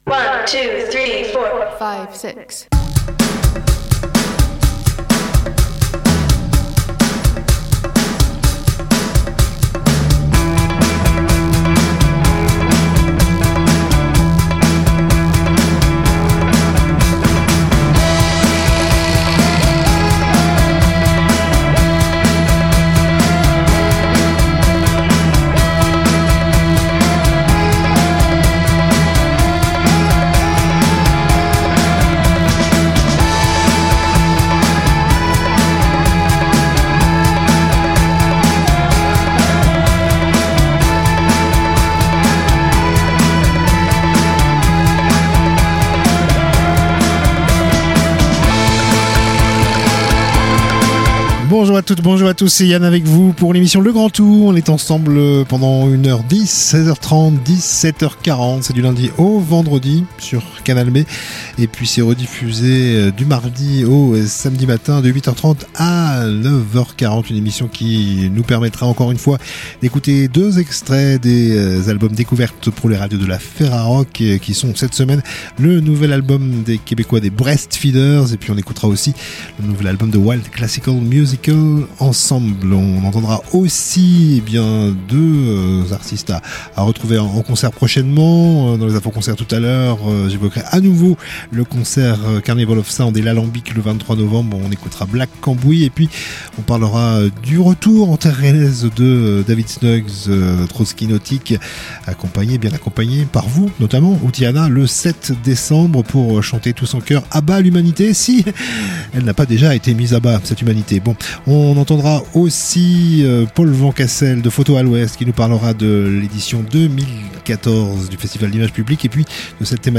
culture Discussion